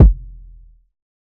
TC2 Kicks11.wav